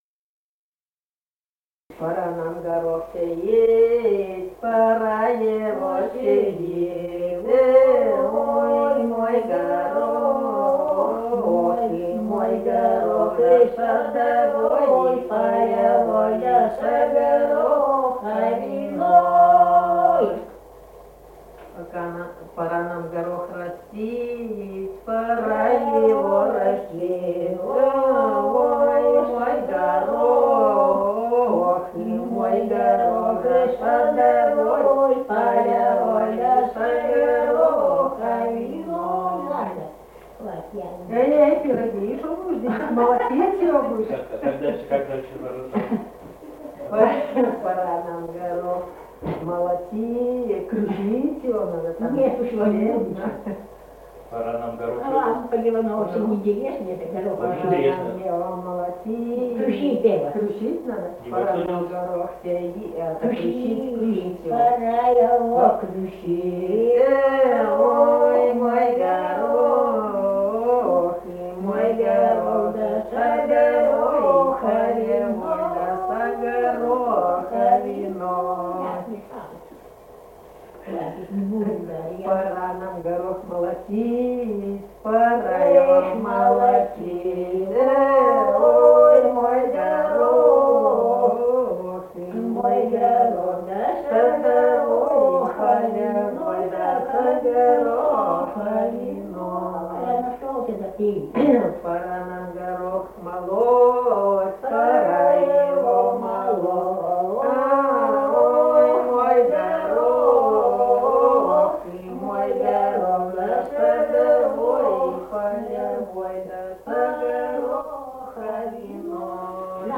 | filedescription = «Пора нам горох сеять», поцелуйный хоровод на вечеринках.
Республика Казахстан, Восточно-Казахстанская обл., Катон-Карагайский р-н, с. Белое, июль 1978.